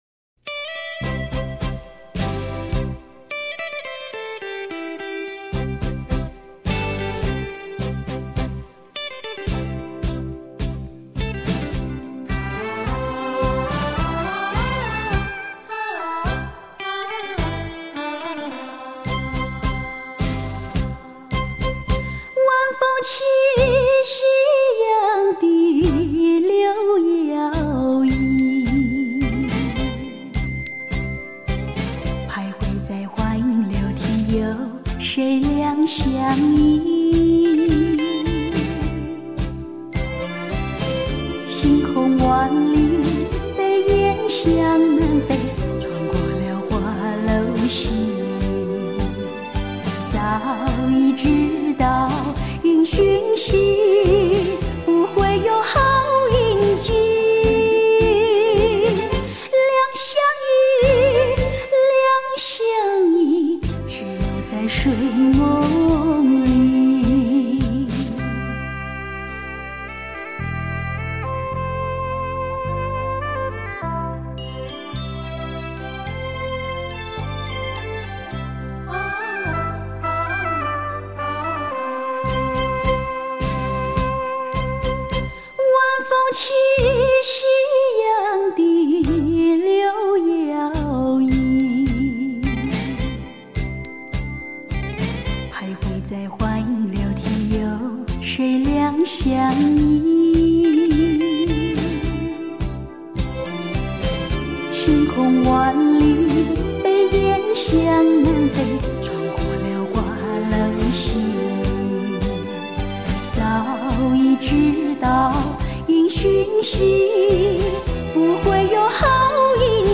探戈名曲